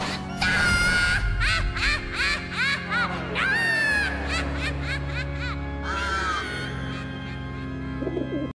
Play Satania Laugh - SoundBoardGuy
Play, download and share Satania Laugh original sound button!!!!
satania-laugh.mp3